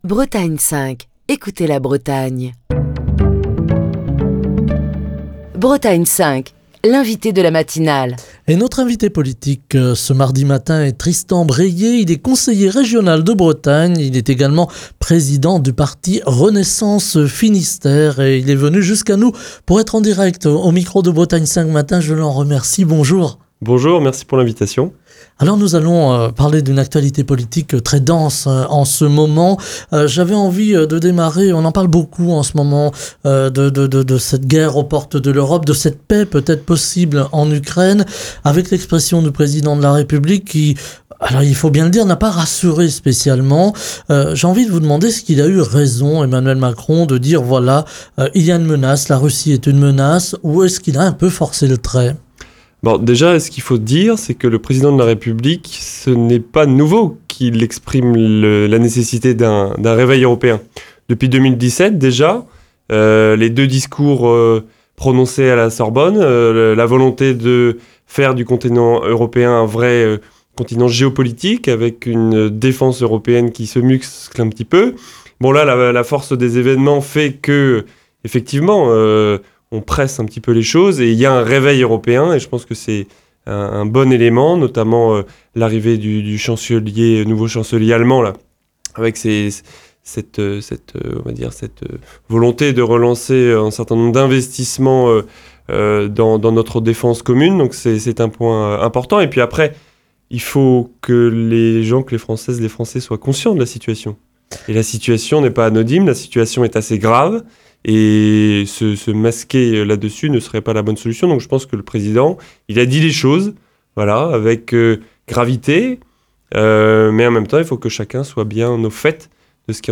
Tristan Bréhier, conseiller régional de Bretagne et président de Renaissance dans le Finistère, était l'invité politique de Bretagne 5 Matin ce mardi.